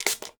SPRAY_Manual_RR2_mono.wav